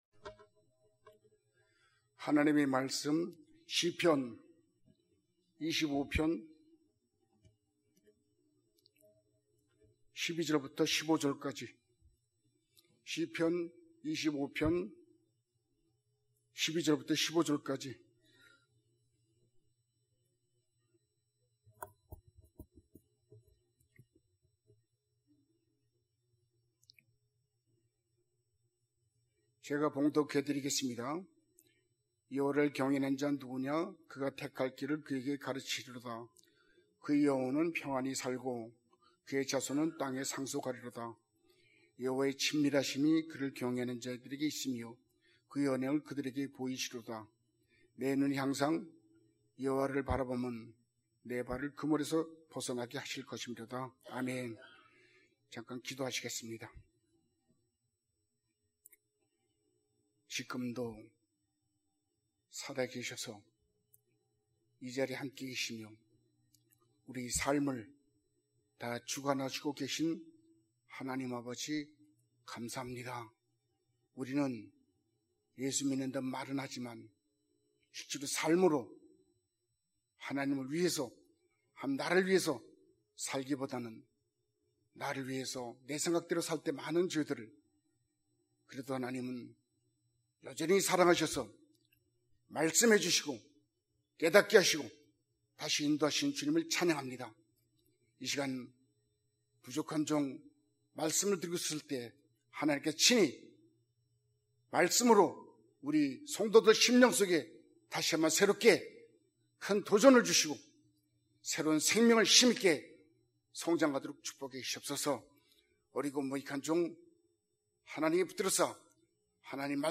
주일예배 - 시편 25장 12절-15절 (오후말씀)